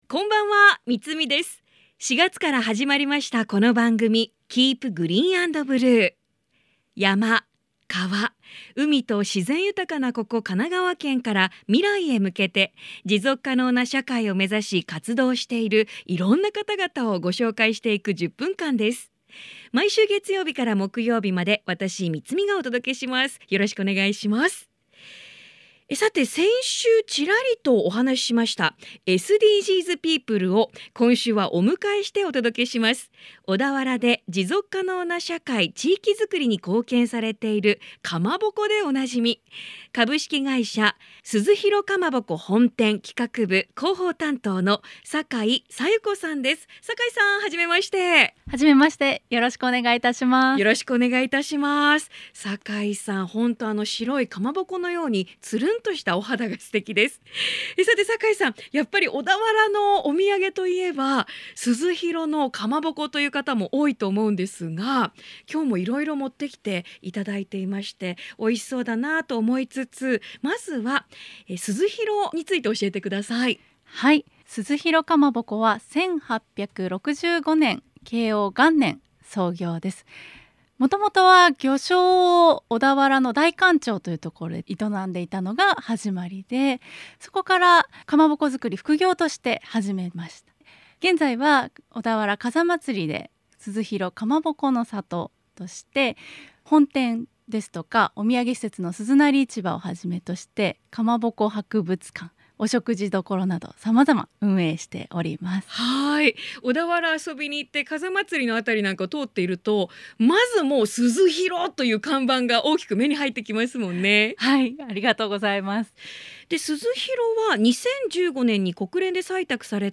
今夜はそんな鈴廣さんのかまぼこをいただきながらの放送となりました。